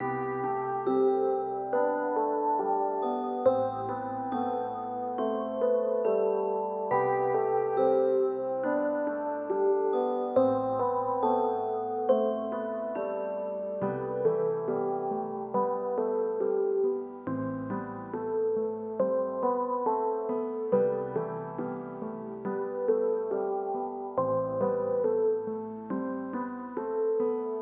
Carousel Love G BPM 139.wav